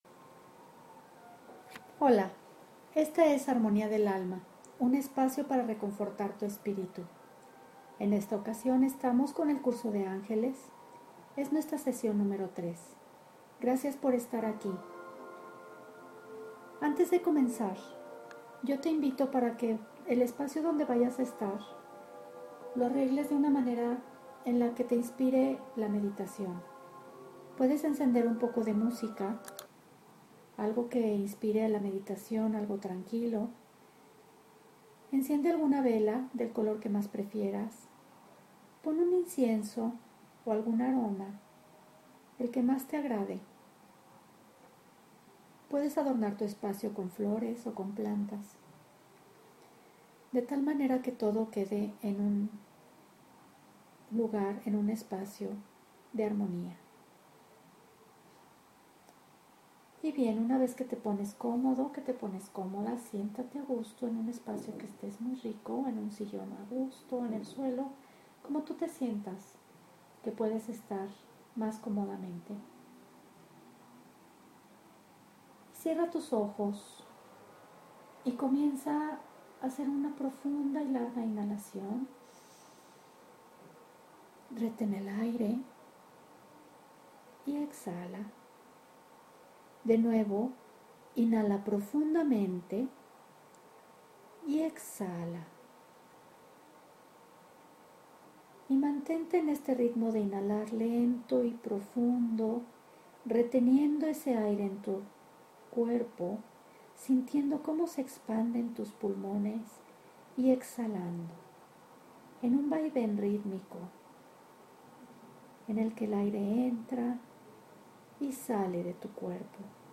CLASE 3: Contactando a tu Ángel de la Guarda